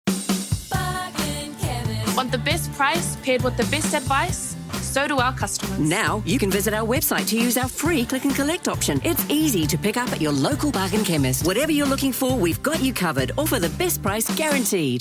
I have my own home studio and can deliver quickly.
My voice is described as warm, friendly, and authentic, perfect for a multitude of different genres I am confident in French, and German, offering versatility for international projects.